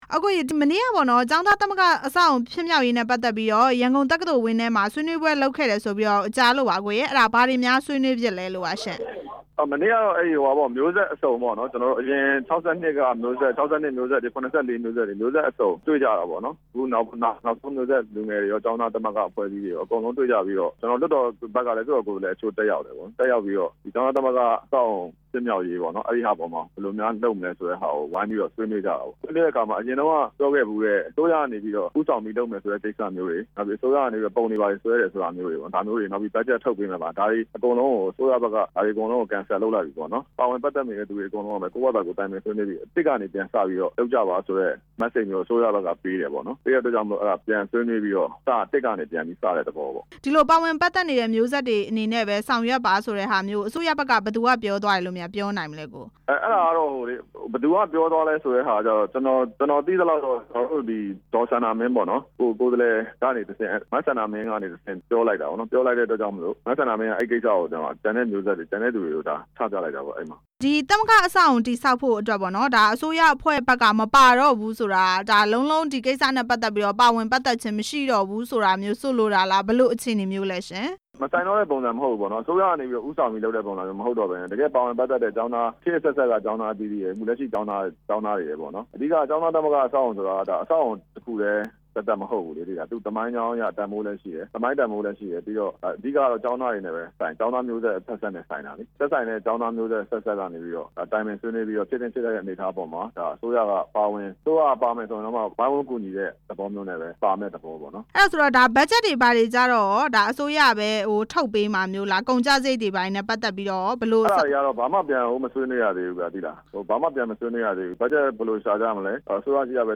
ကျောင်းသားသမဂ္ဂ အဆောက်အအုံ တည်ဆောက်ရေးနဲ့ မေးမြန်းချက်